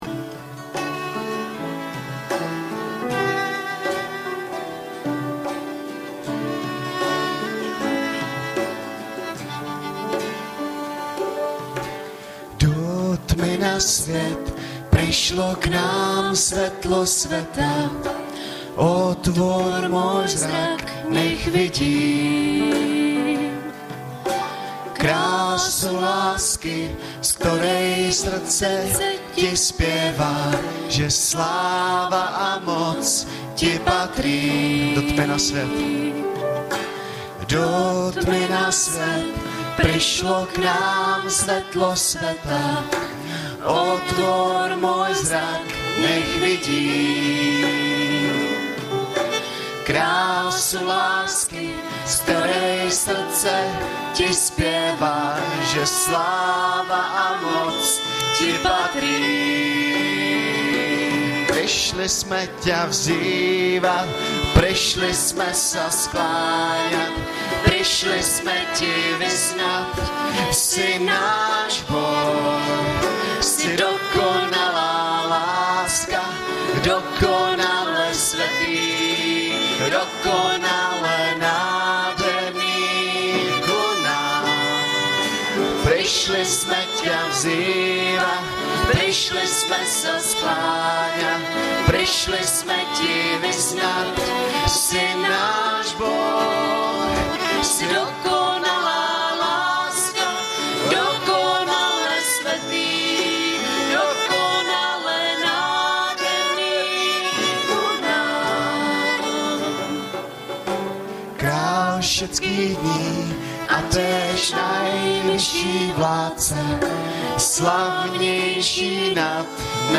Hlavní nabídka Kázání Chvály Kalendář Knihovna Kontakt Pro přihlášené O nás Partneři Zpravodaj Přihlásit se Zavřít Jméno Heslo Pamatuj si mě  25.12.2012 - VÁNOCE NAROZENÍM NEKONČÍ - Luk 2 Audiozáznam kázání si můžete také uložit do PC na tomto odkazu.